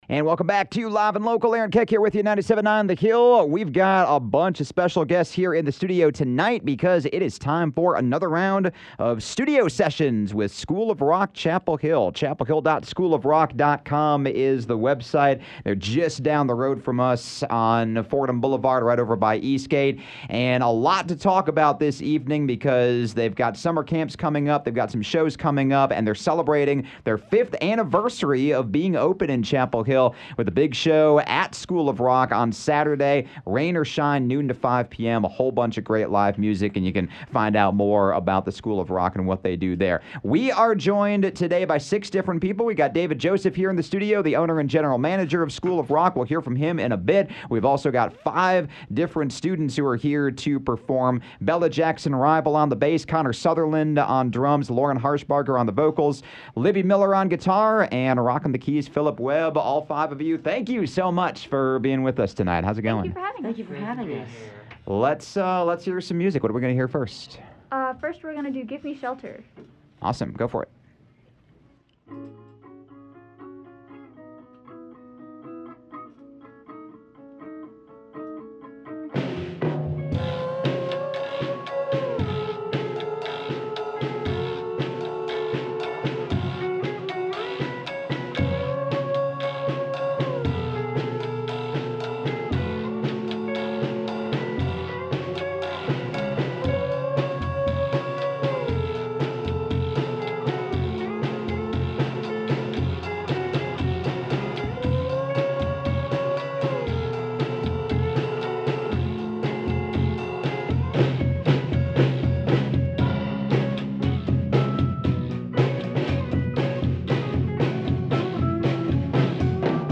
It’s time for another round of Studio Sessions with the School of Rock Chapel Hill, featuring three classic hits performed by the House Band!